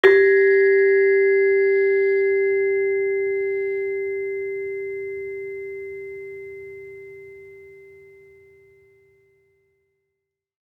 Saron-3-G3-f.wav